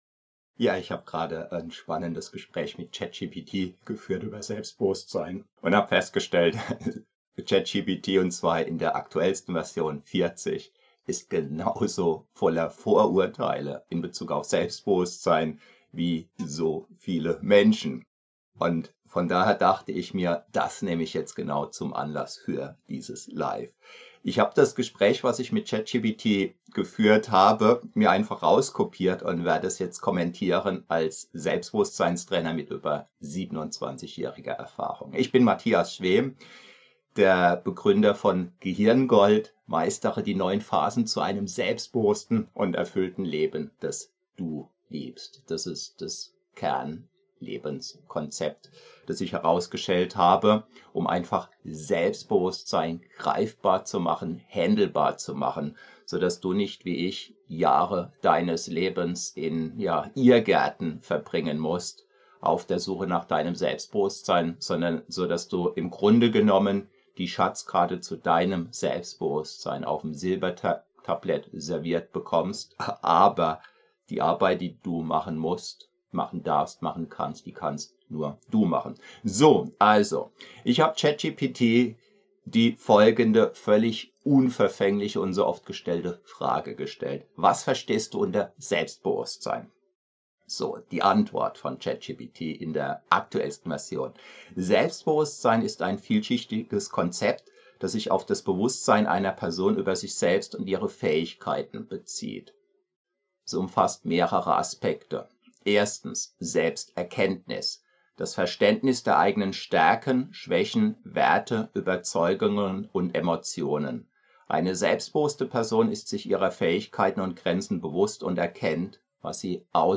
Mit über 27 Jahren Erfahrung als Selbstbewusstseinstrainer kommentiere ich live, wie man ein realistisches Selbstbild bewahrt und welche Gefahren ein übertriebenes Selbstwertgefühl mit sich bringen kann.
0:00 Gespräch mit Chat GPT über Selbstbewusstsein